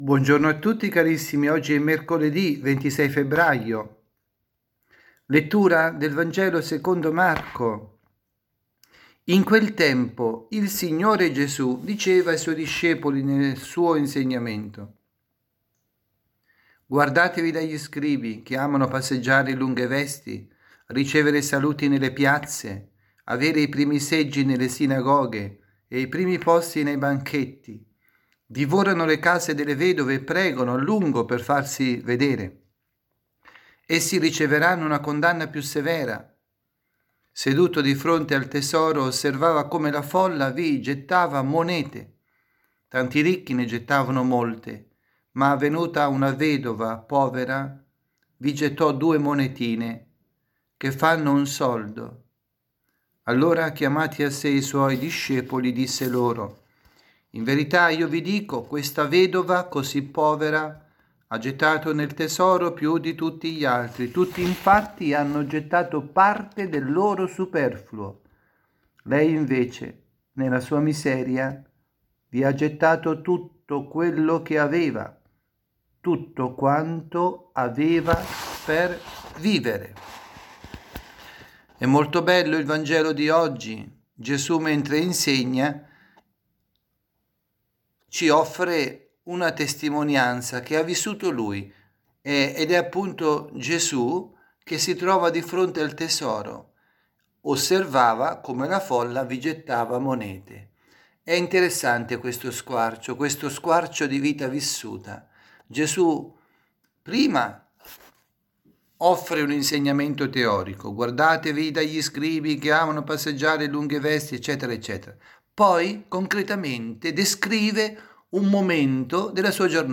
avvisi, Omelie